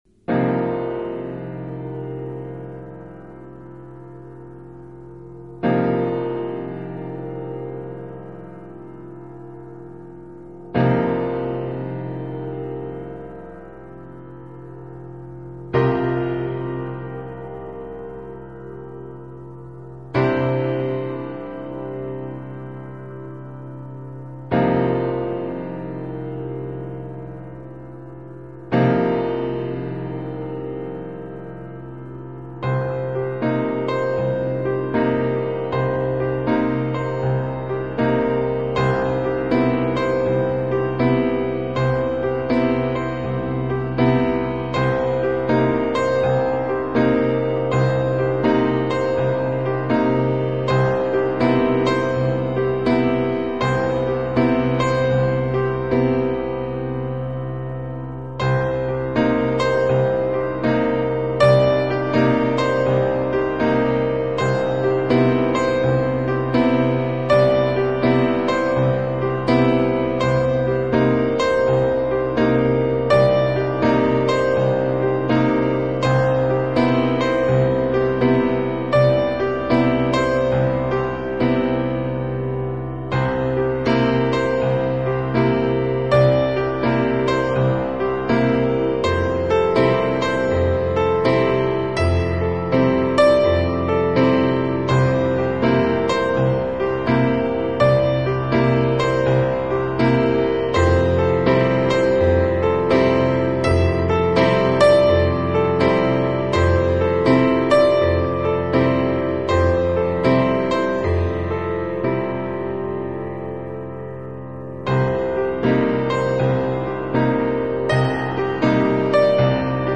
Style: New Age, Instrumental, Neo-classical, Piano Solo